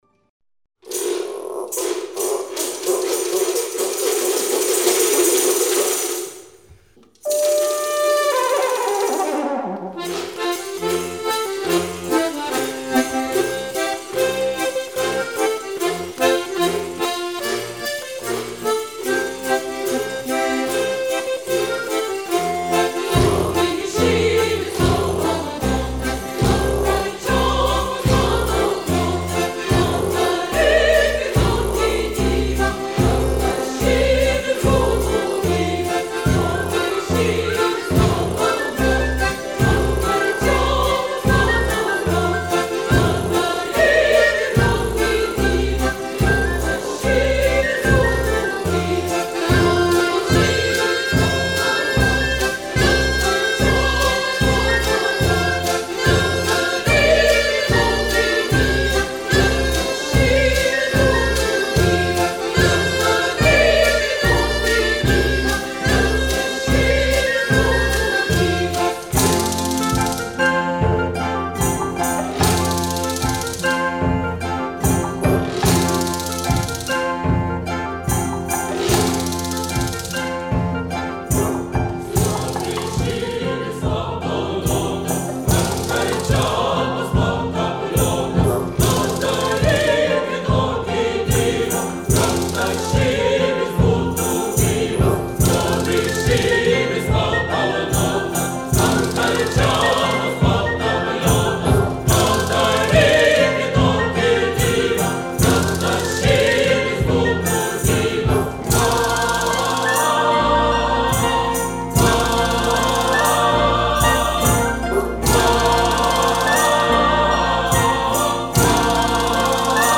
Folklorinis šokis „Šyvis“